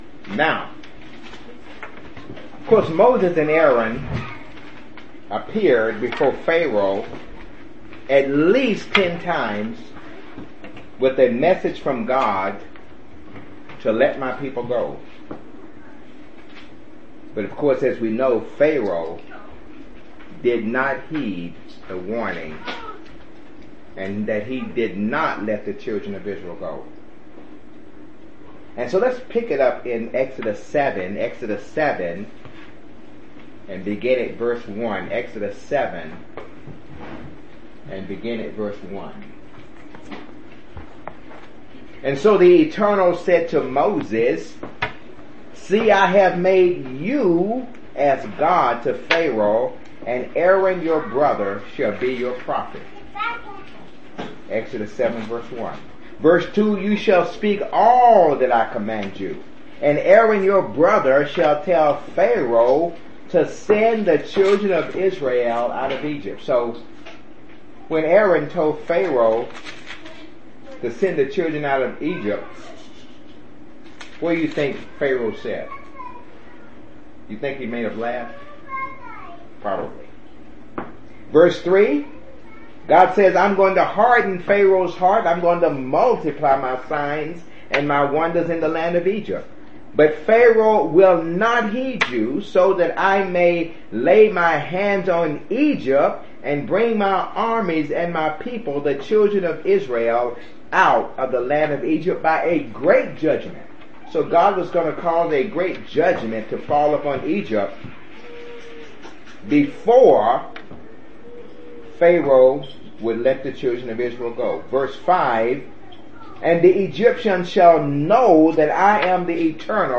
Given in Jackson, TN
UCG Sermon Studying the bible?